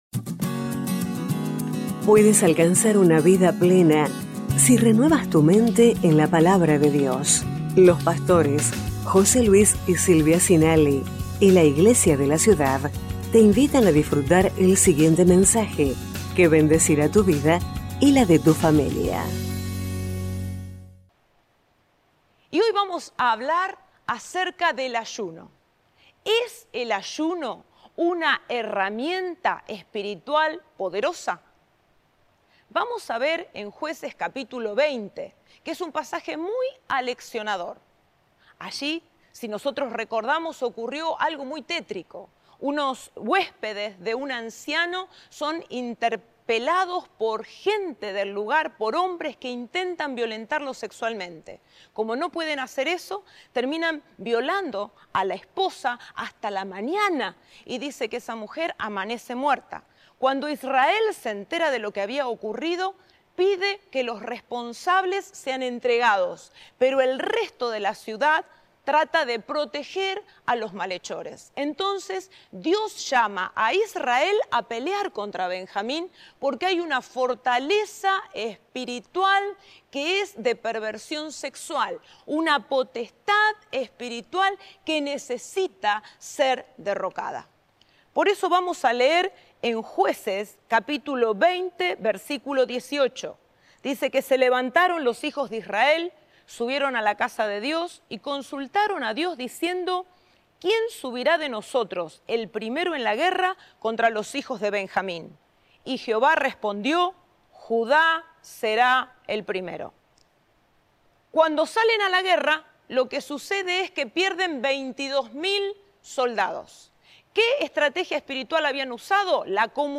Iglesia de la Ciudad - Mensajes